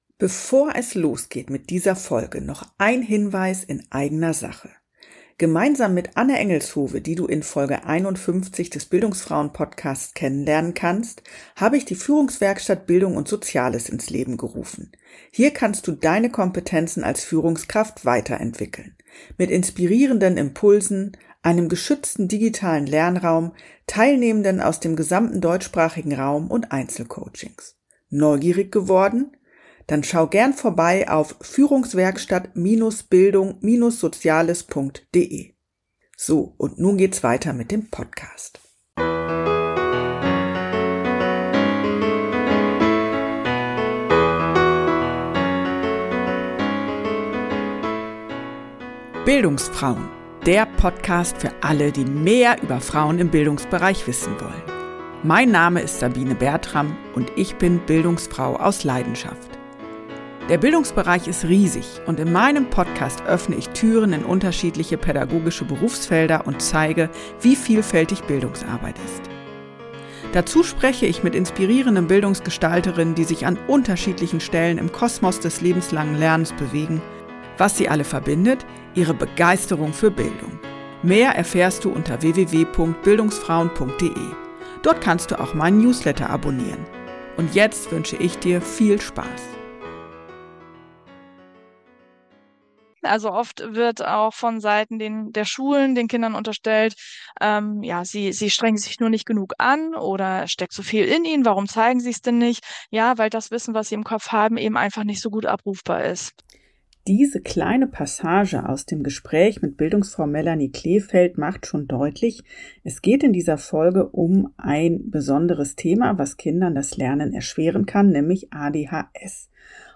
Den Link zur Mammutfolge findet ihr in den Shownotes - und jetzt viel Spaß mit diesem Gespräch!